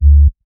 F#_07_Sub_01_SP.wav